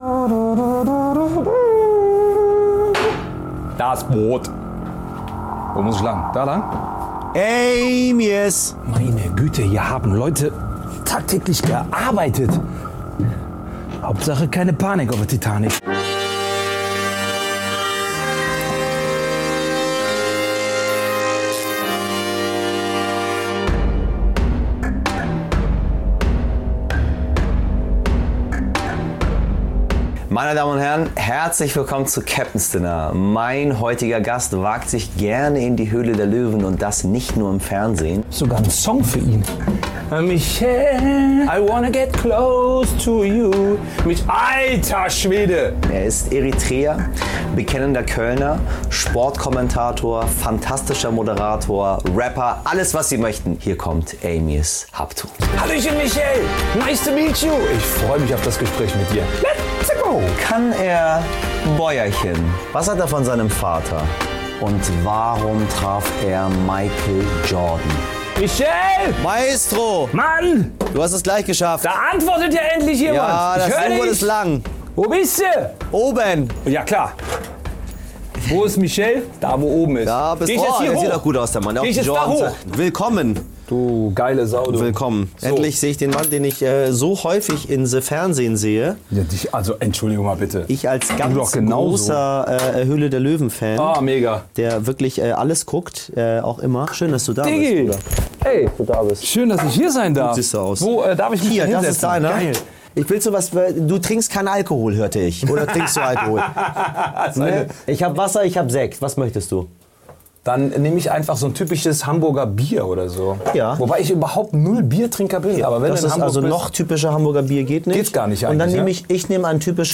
Bei Michel Abdollahi im U-Boot ist der Moderator Amiaz Habtu zu Gast.